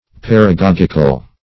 paragogical - definition of paragogical - synonyms, pronunciation, spelling from Free Dictionary
Paragogic \Par`a*gog"ic\, Paragogical \Par`a*gog"ic*al\, a. [Cf.